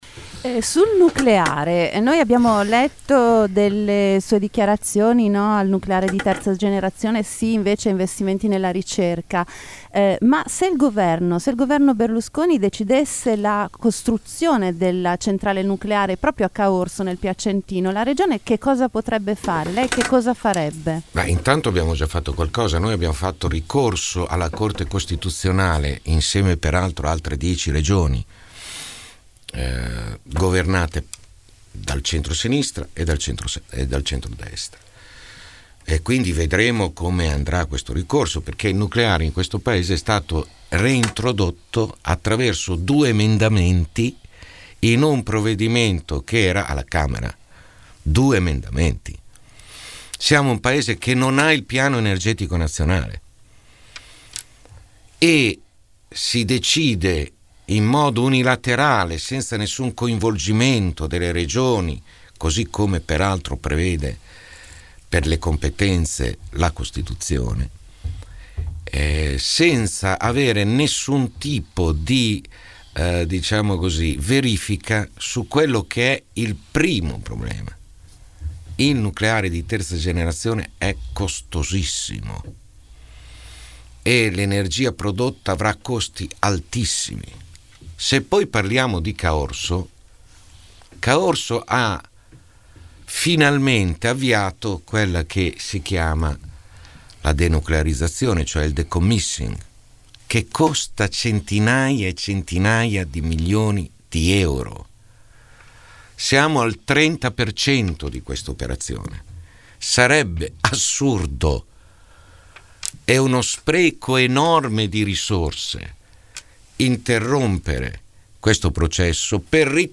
16 mar. – Si concludono con il presidente uscente e candidato del centrosinistra, Vasco Errani, le interviste di Città del Capo – Radio Metropolitana ai candidati alle prossime elezioni regionali (28 – 29 marzo).